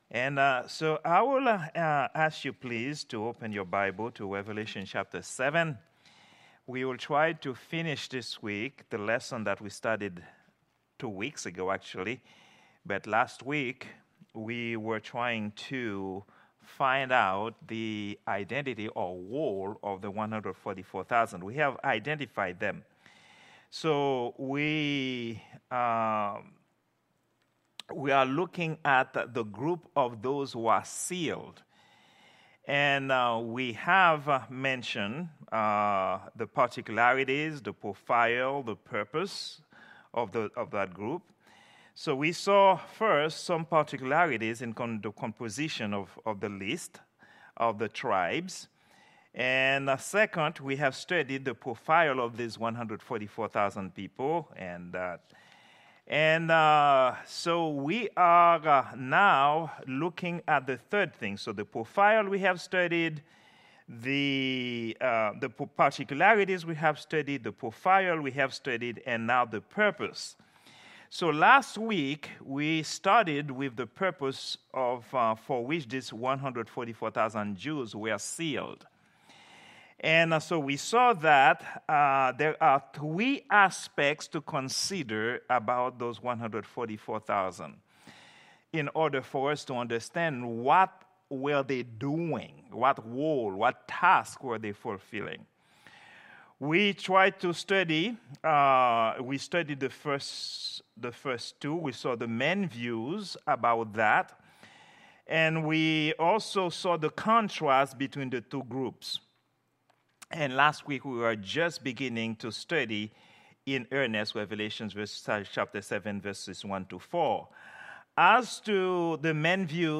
Prayer_Meeting_08_07_2024.mp3